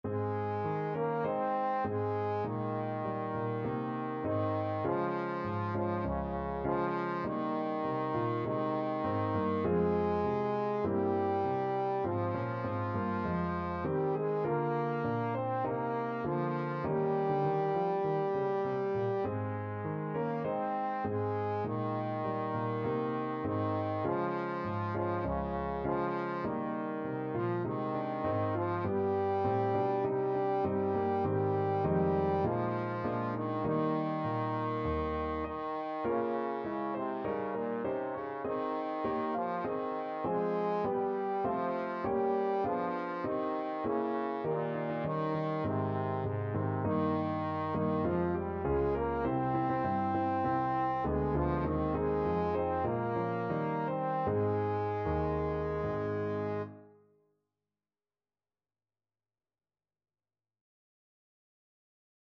Traditional Trad. Amhran na bhFiann (A Soldier's Song) (Irish National Anthem) Trombone version
Trombone
Ab major (Sounding Pitch) (View more Ab major Music for Trombone )
4/4 (View more 4/4 Music)
Ab3-C5
Traditional (View more Traditional Trombone Music)